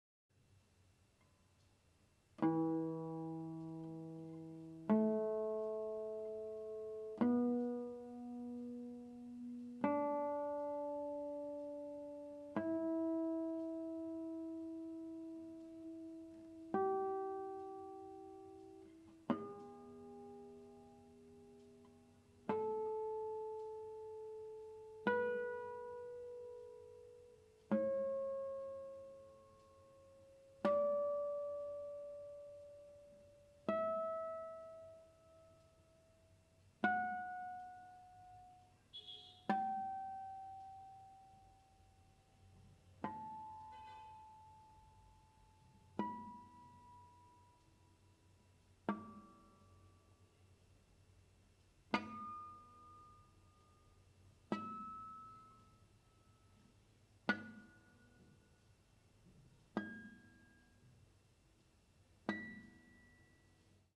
78. Harmónicos naturais.m4v